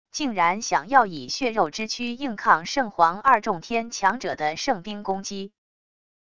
竟然想要以血肉之躯硬抗圣皇二重天强者的圣兵攻击wav音频